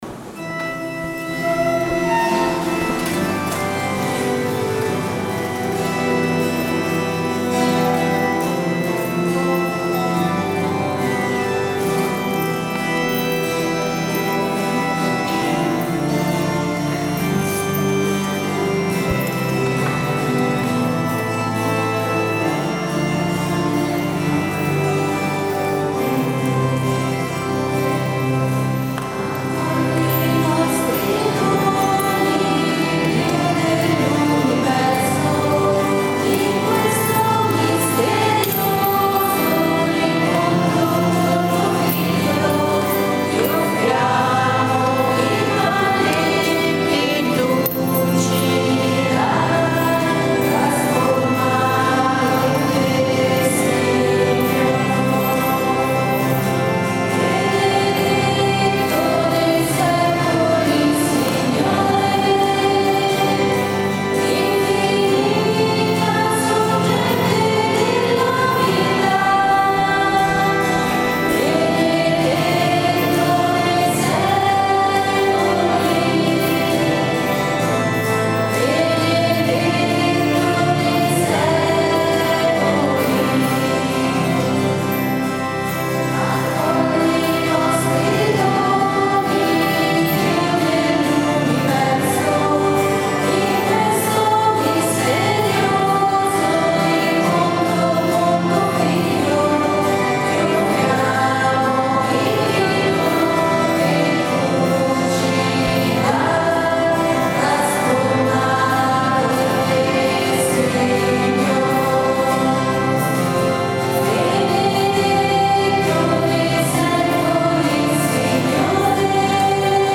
Offertorio